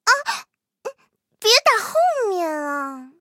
M10狼獾中破语音.OGG